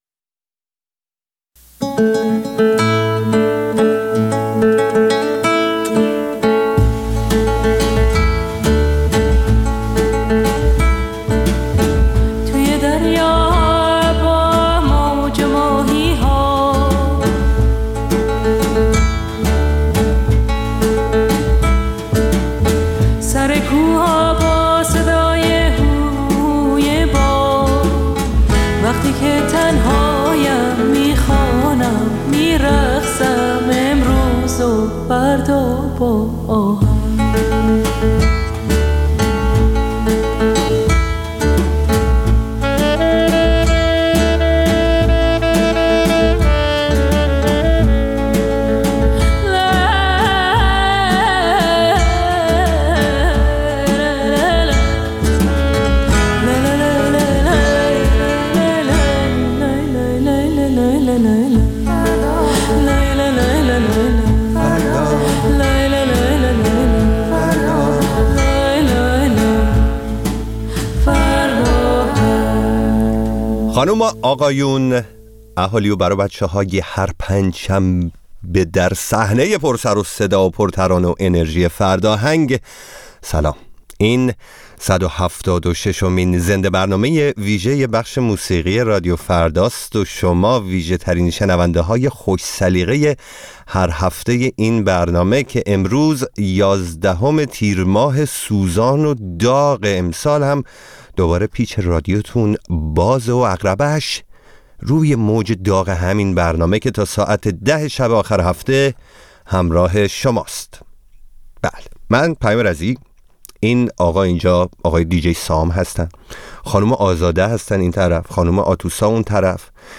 برنامه زنده بخش موسیقی